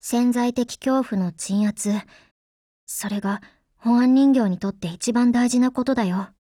贡献 ） 协议：Copyright，其他分类： 分类:少女前线:SP9 、 分类:语音 您不可以覆盖此文件。